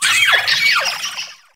wugtrio_ambient.ogg